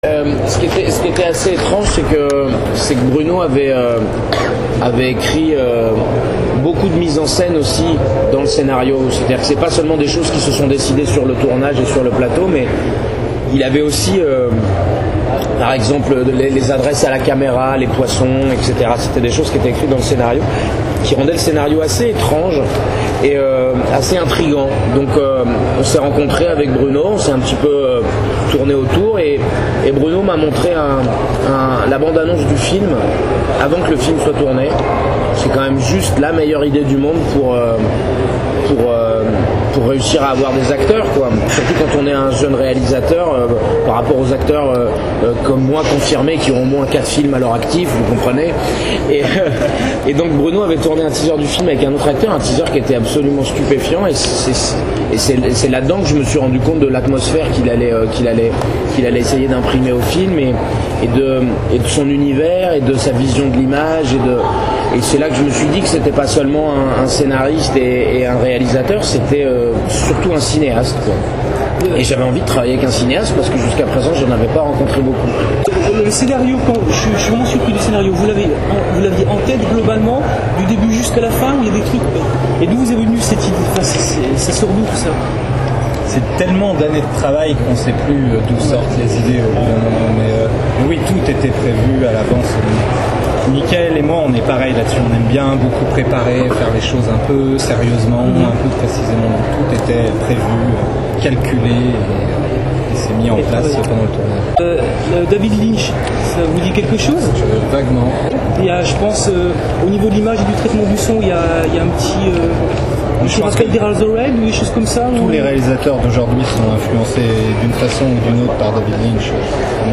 �coutez cet interview disponible en version audio int�grale